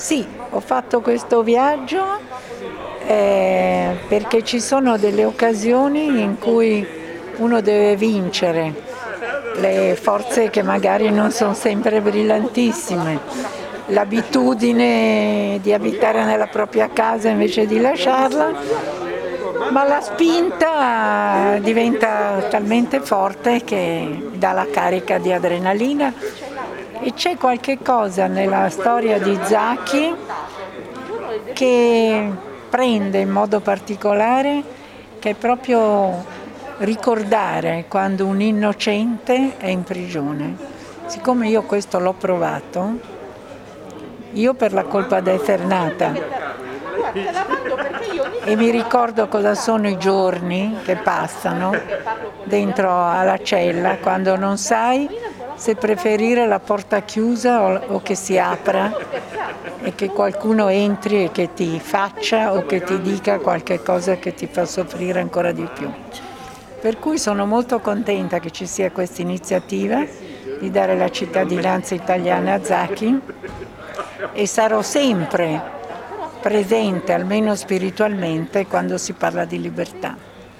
Sentiamola ai nostri microfoni: